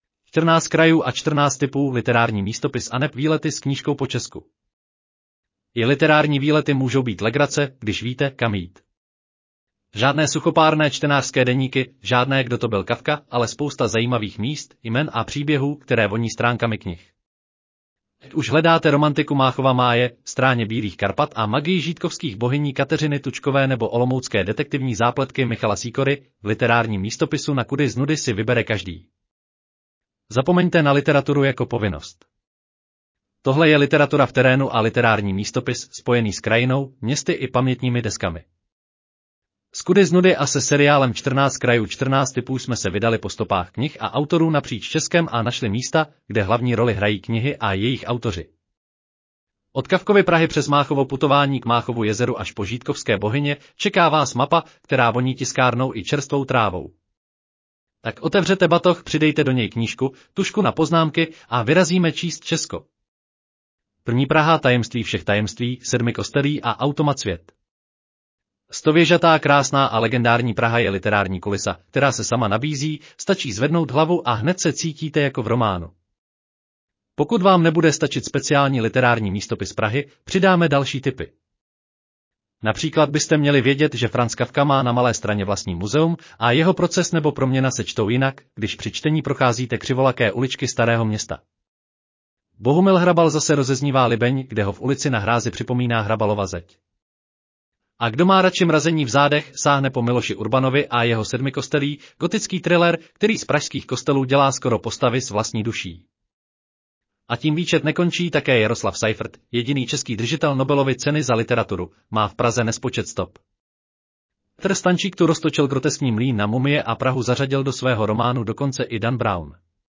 Audio verze článku Čtrnáct krajů & čtrnáct tipů: literární místopis aneb výlety s knížkou po Česku